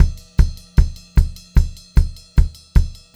152SPCYMB4-R.wav